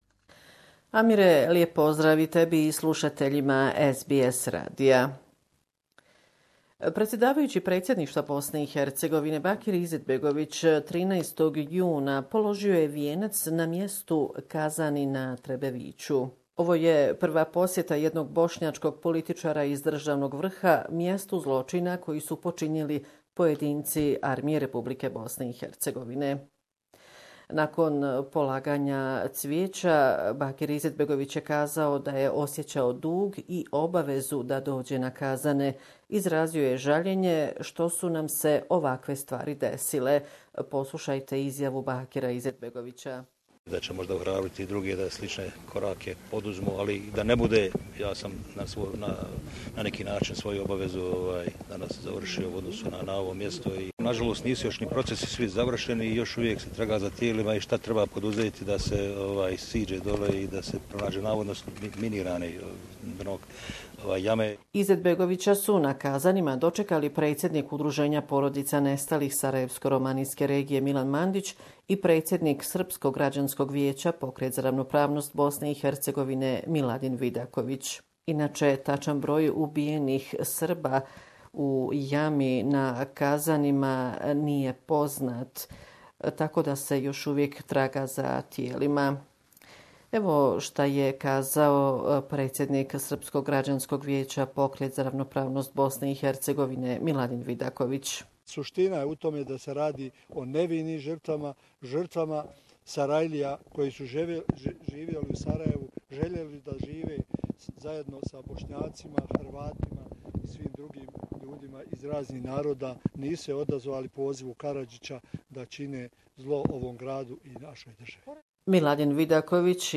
Report from Bosnia and Herzegovina Mr. Bakir Izetbegovic, the member of the Bosnian Presidency, paid an official visit to Kazani (near Sarajevo) to commemorate the Serbs killed in 1990s'